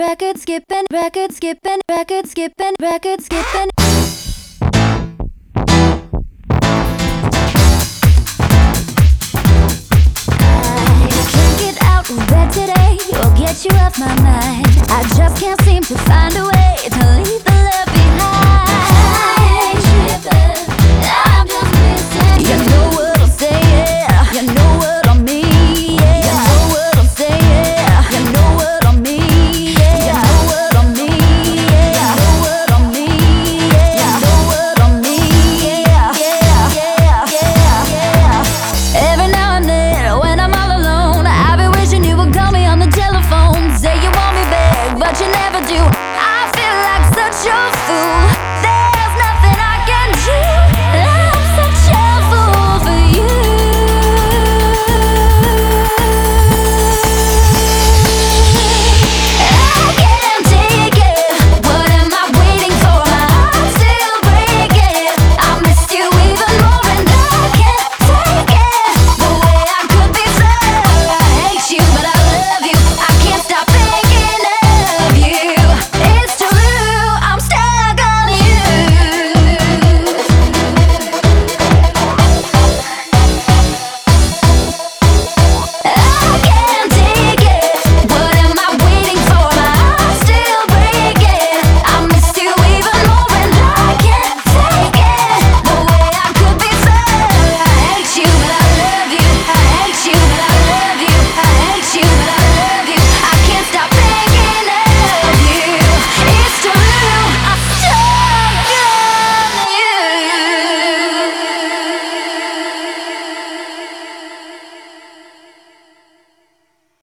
BPM100-127
Audio QualityPerfect (High Quality)